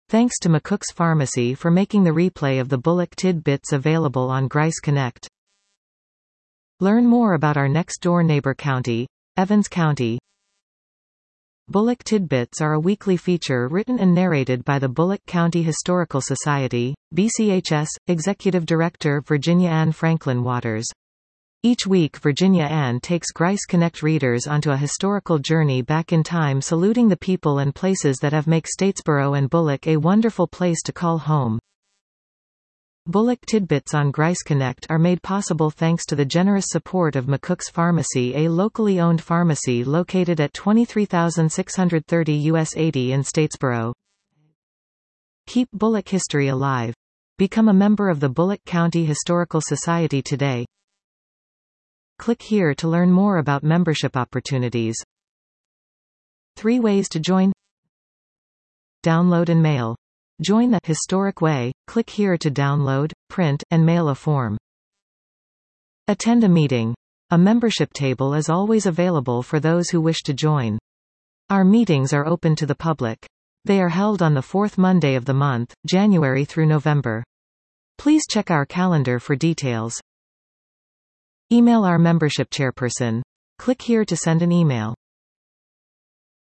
Bulloch Tidbits are a weekly feature written and narrated by the Bulloch County Historical Society